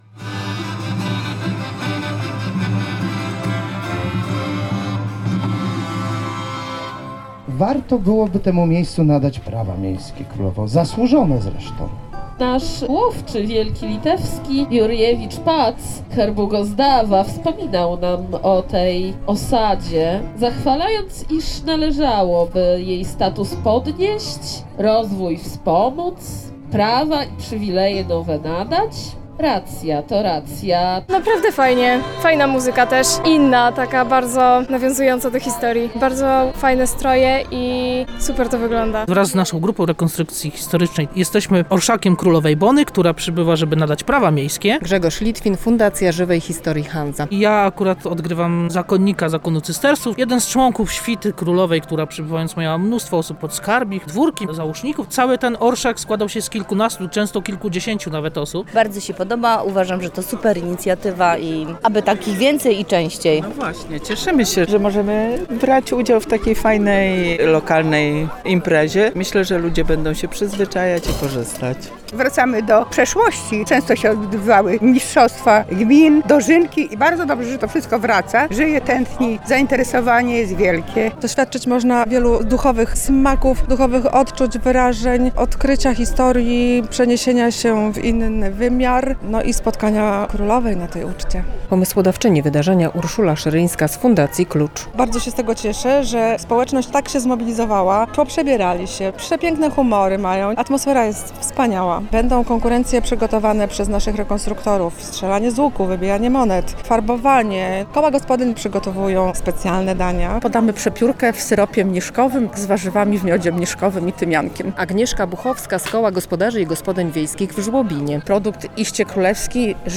W Berżnikach na Sejneńszczyźnie świętowano festiwal "Ucztę u Królowej Bony".